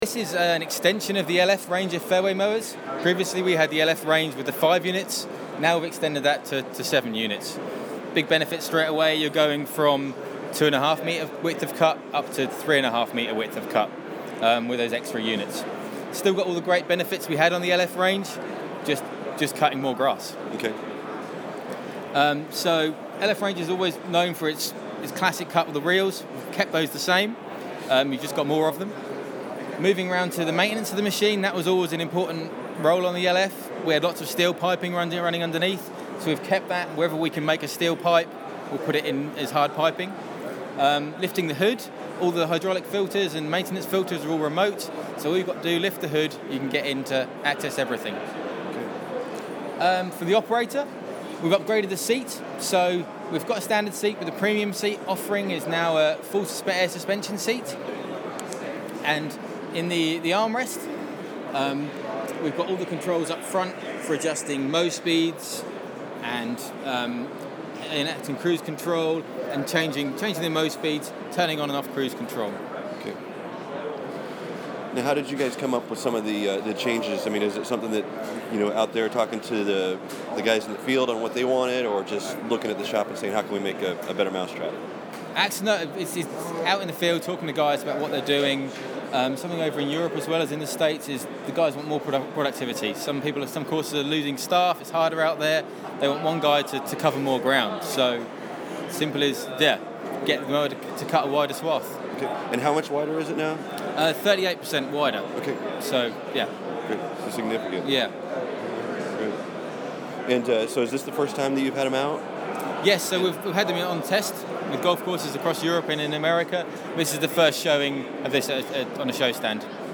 It was my second trip to the BIGGA Turf Management Exhibition in Harrogate, England, so while I wasn’t as wide-eyed as I was my first time attending the show, I still was impressed to see equipment that hasn’t yet been out in the open in the U.S.
Along with the interviews below, we also took video of each item for an exclusive Golfdom TV video tour.